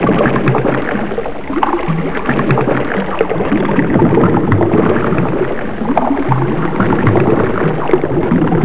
bubbling.wav